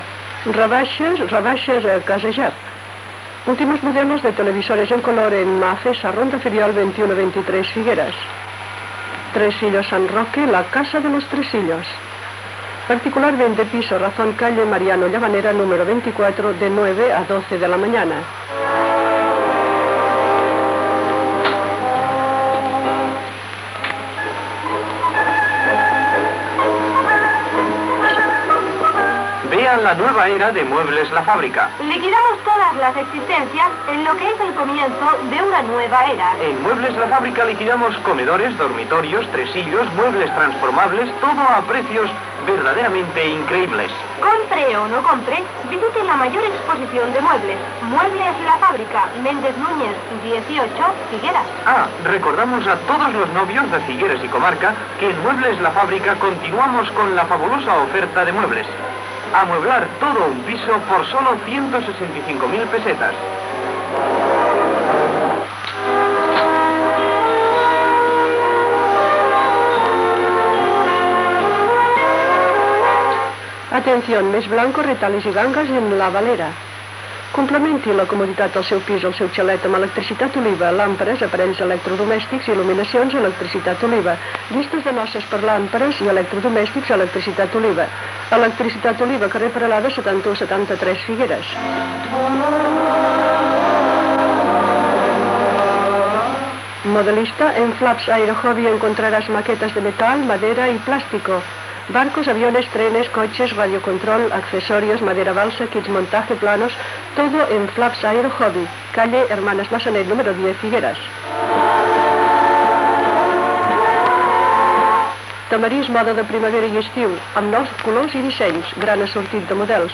Publicitat local llegida i alguns anuncis enregistrats, hora, presentació del programa, equip, data
Info-entreteniment